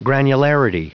Prononciation du mot granularity en anglais (fichier audio)
granularity.wav